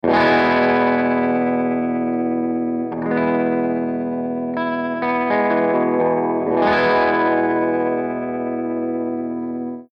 014_AC30_VIBRATO2_P90